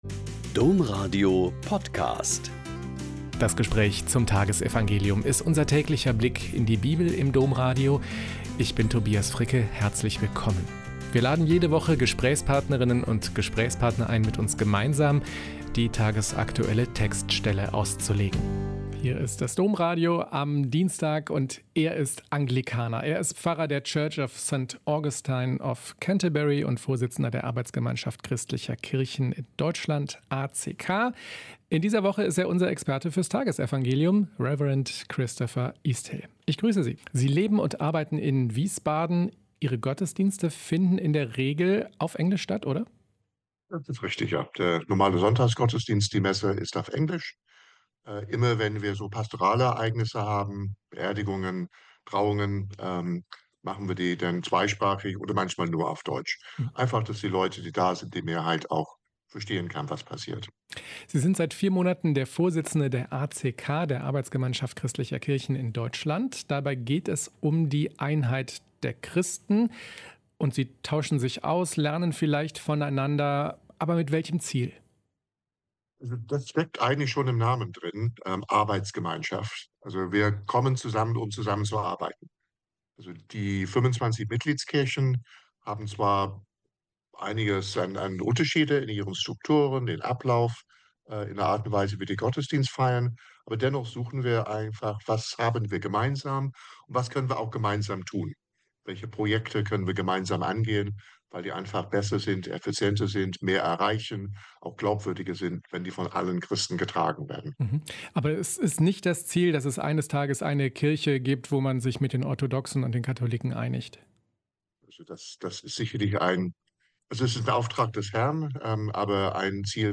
Joh 20,1-2.11-18 - Gespräch